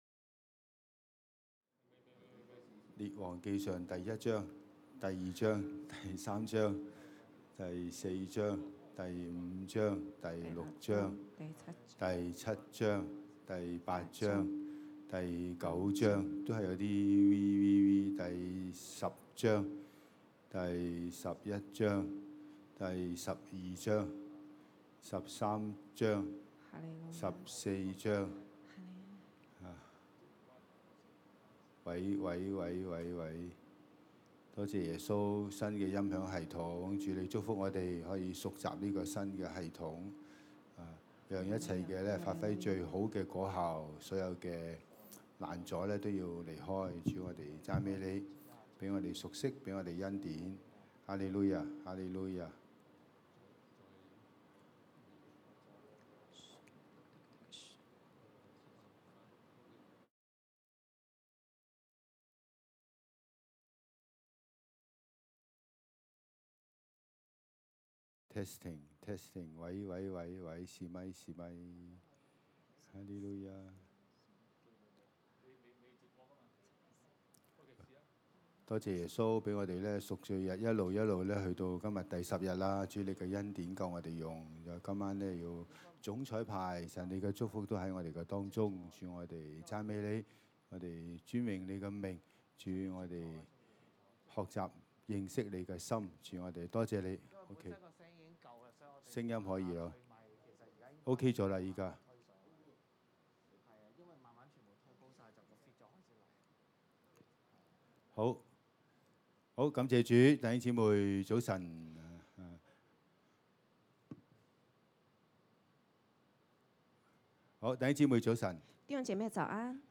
2) 禱告認罪今章看到以色列南北兩國列王所犯的罪，有許多的瑕疵和軟弱，總結有以下六點： a)不完全順服神 b)不夠信心，找外人幫助 c)搶奪別人 d)偏行己路 e)引誘人拜偶像 f) 背叛 今天是敬畏十日的第十天，在現場的弟兄姊妹，我們一起跪下向神認罪，而線上的弟兄姊妹亦可透過聊天室認罪。